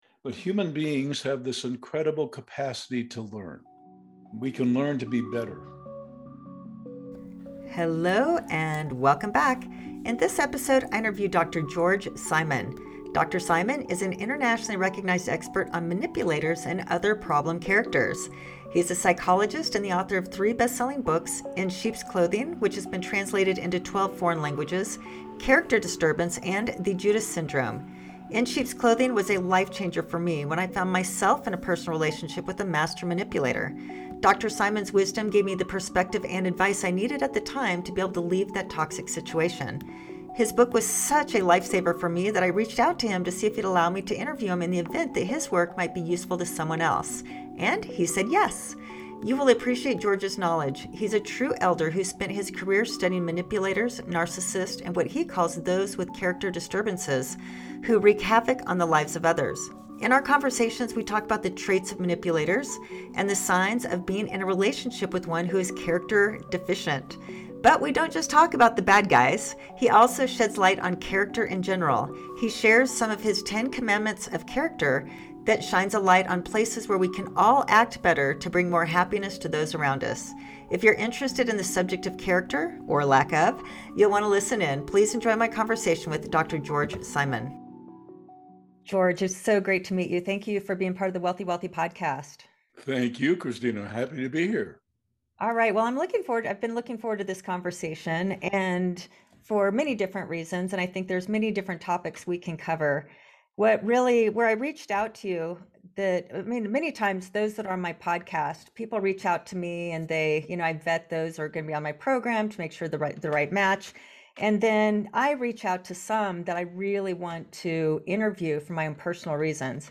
In our conversation, I reminisced on a time I decided to speak up about what I was facing (relationally), which turned out to be the catalyst to my healing!